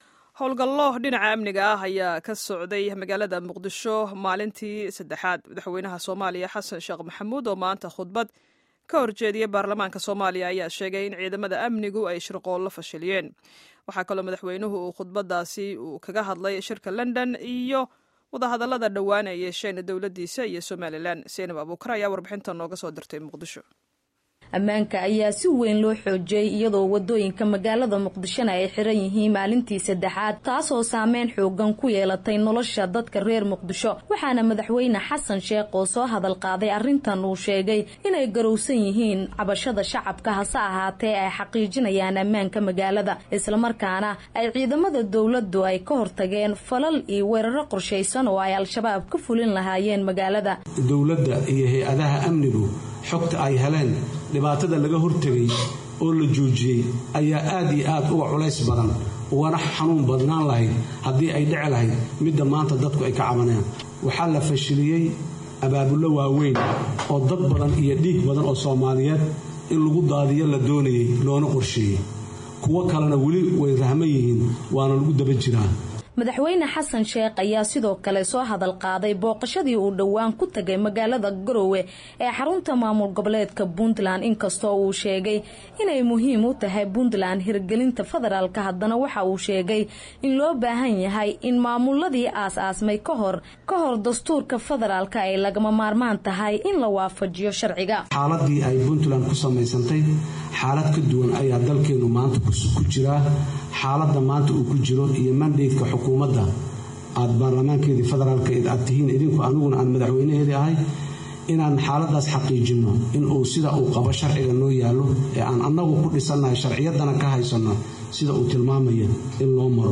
Warbixinta Khudbadda Madaxweynaha